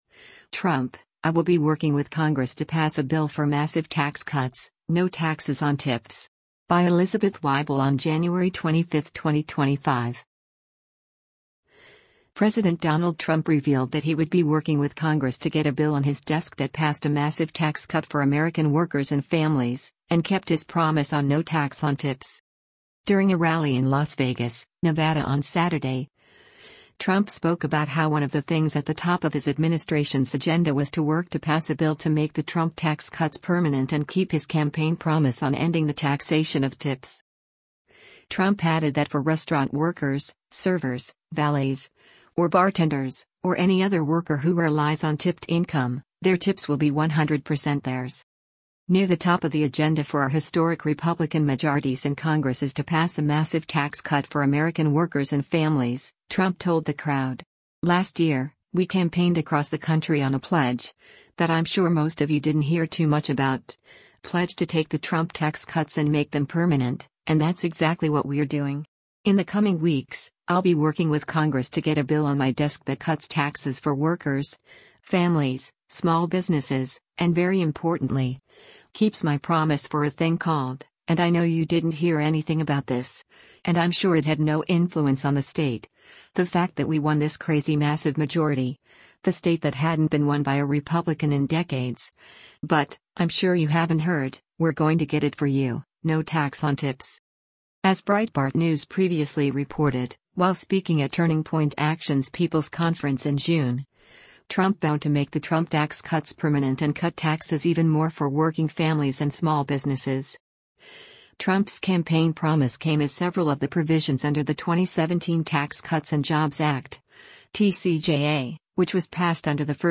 U.S. President Donald Trump speaks at a rally at Circa Resort & Casino on January 25,